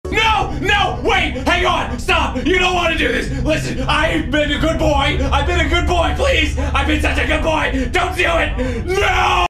markiplier no wait ive been a good boy Meme Sound Effect
Category: Reactions Soundboard